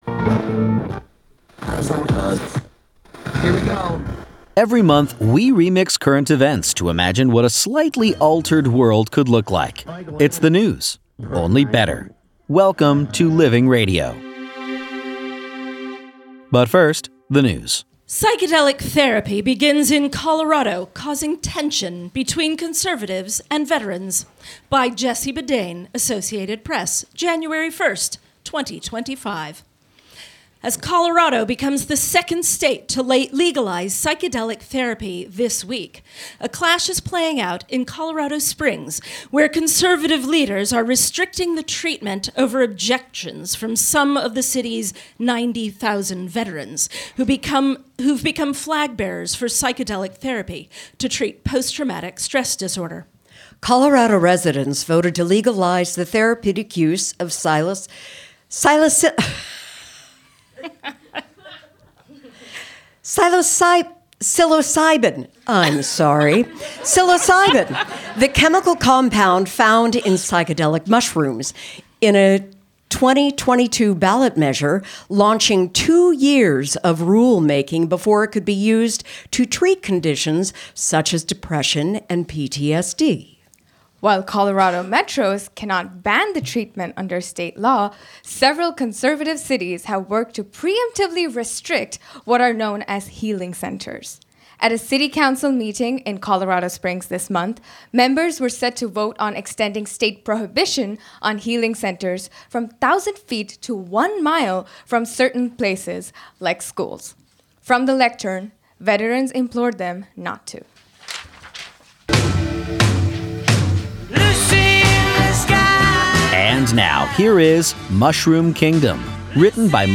performed live at UNDER St. Mark’s Theater, January 6, 2025